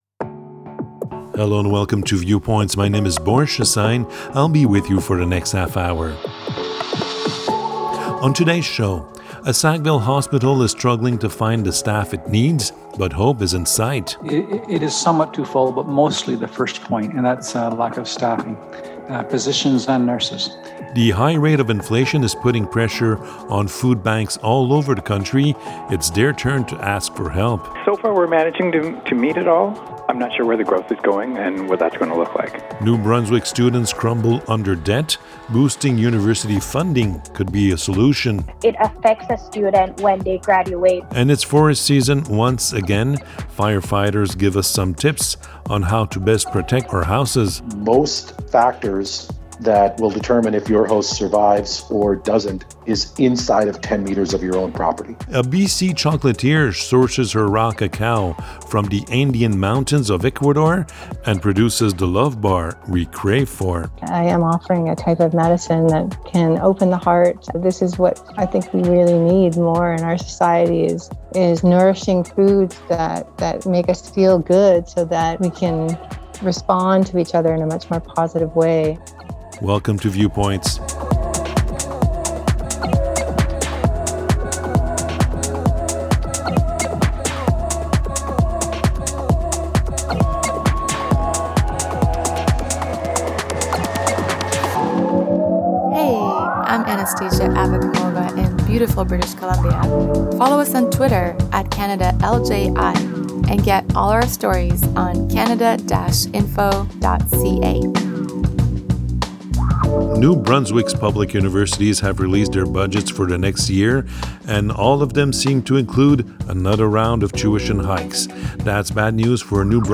Viewpoints, season 1, ep.15 Viewpoints is a half-hour magazine aired on 30 radio stations across Canada.